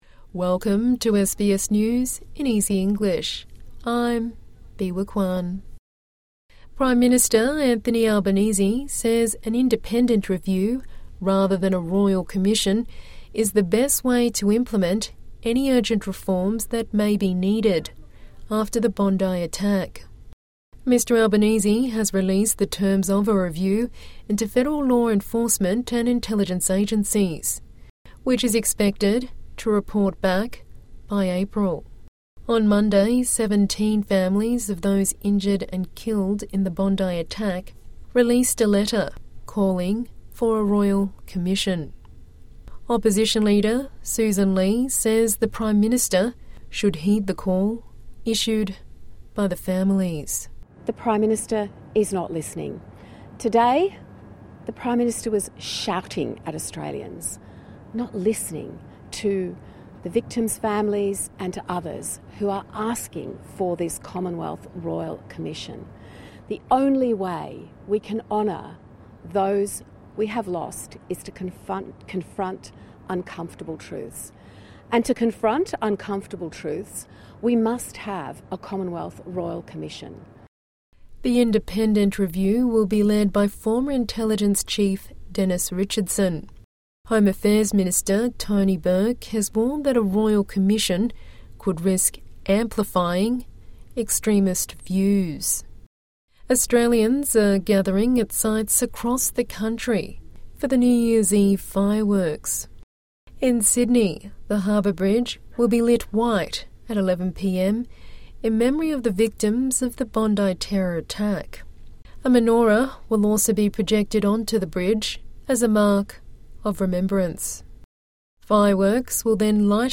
A daily 5 minute bulletin for English learners and people with a disability.